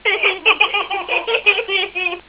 rires.wav